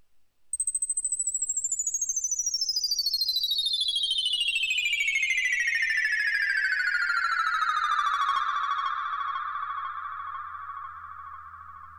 на 15 секунде слышен интересный звук, похожий на шуршание